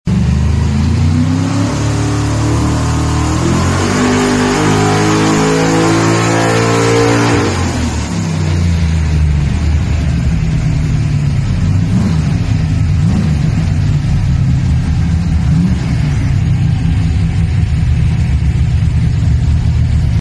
Excuse the very poor quality sound effects free download